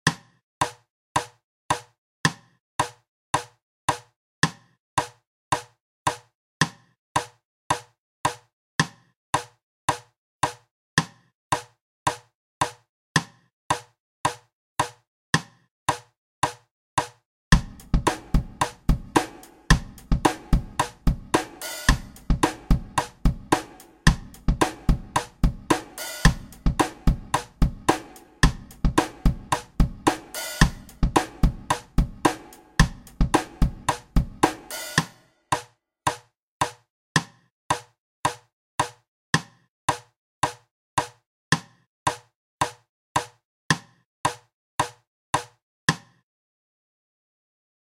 Nedan finns ljudfiler och tillhörande bilder för 4-takt och 3-takt.
Trupp A 4-takt (mp4)